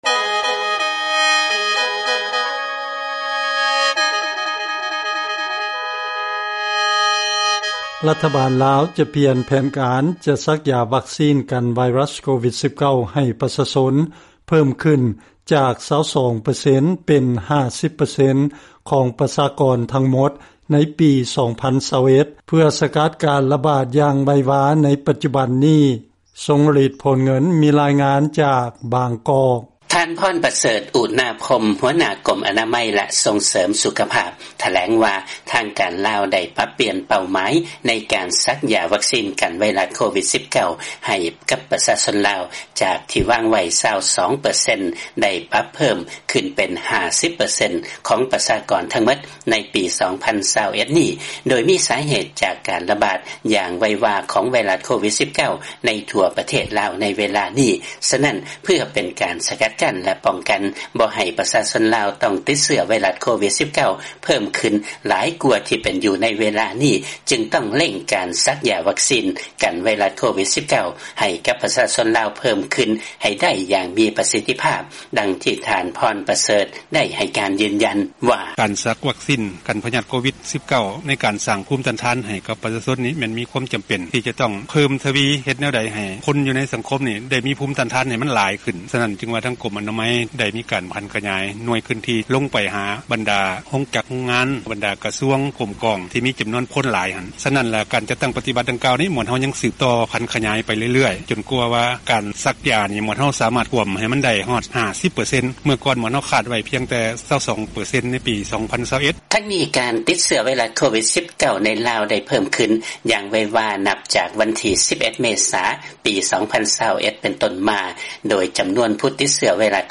ເຊີນຟັງລາຍງານກ່ຽວກັບລັດຖະບານລາວມີແຜນສັກຢາວັກຊີນໃຫ້ໄດ້ ເຄິ່ງນຶ່ງຂອງພົນລະເມືອງລາວໃນປີນີ້